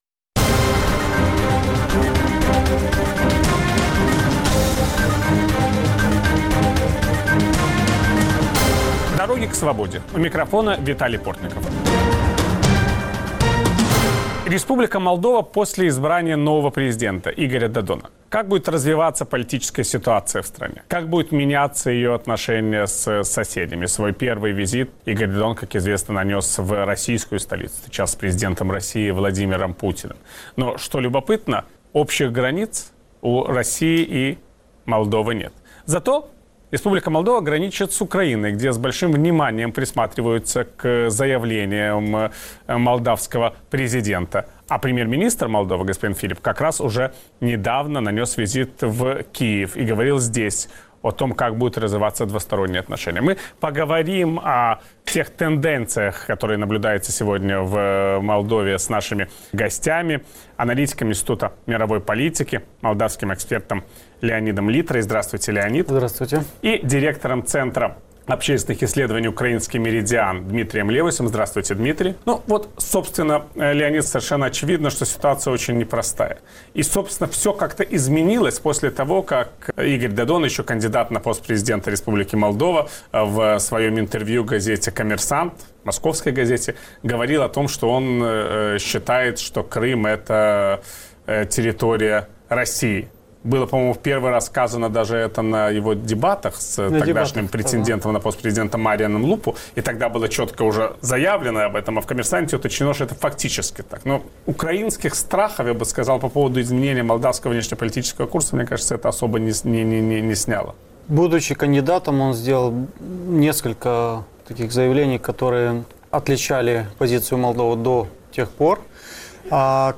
Как будут складываться украинско-молдавские отношения после победы пророссийского кандидата на президентских выборах в Молдове? Собсеседники Виталия Портникова